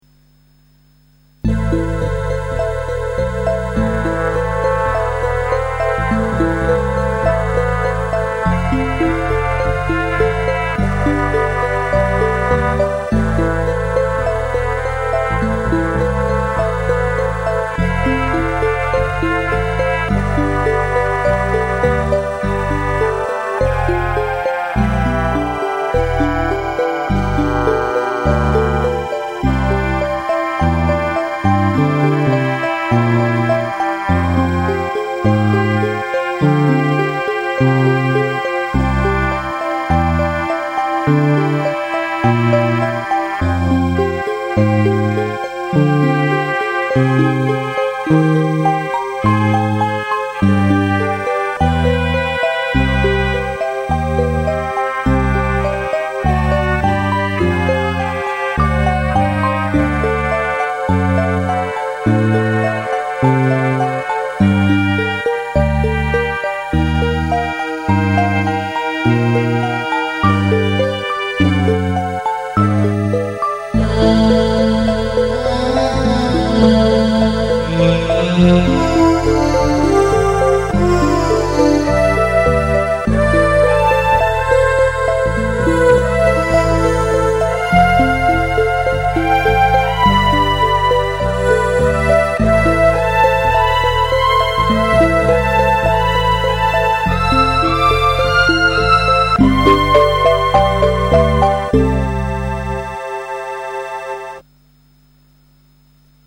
LucyTuned song